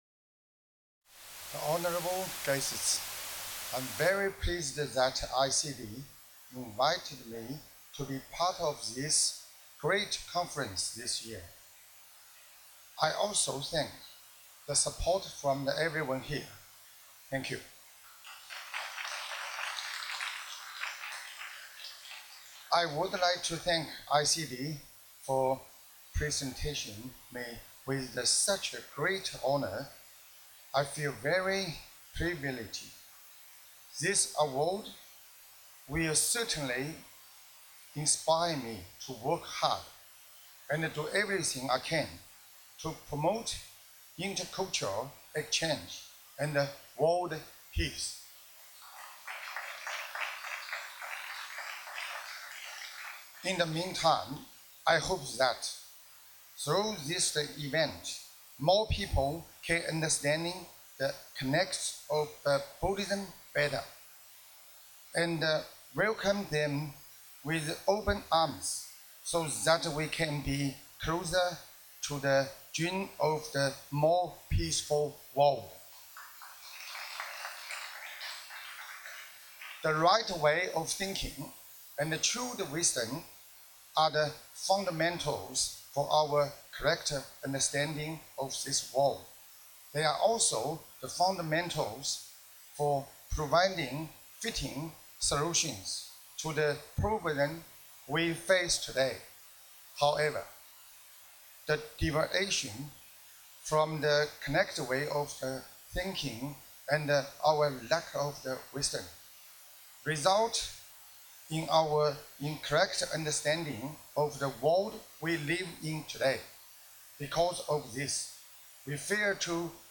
德国柏林“全球文化外交峰会”